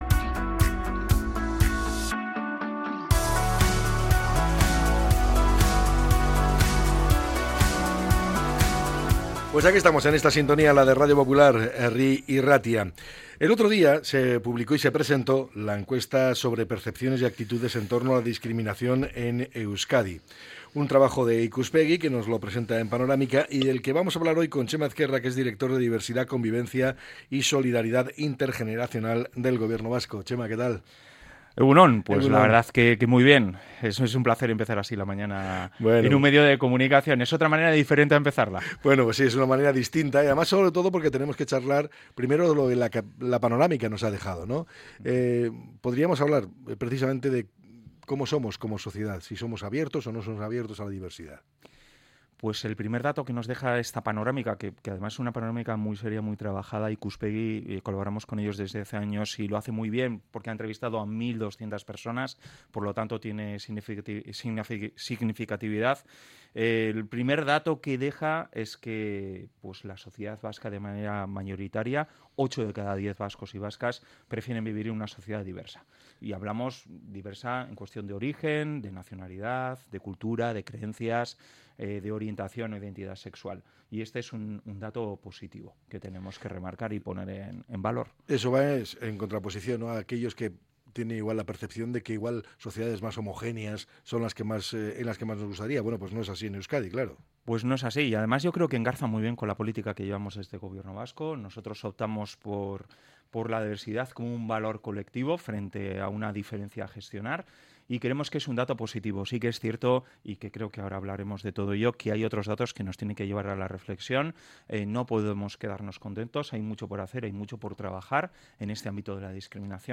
Hablamos con Txema Ezkerra, director de Diversidad, Convivencia y Solidaridad Intergeneracional del Gobierno Vasco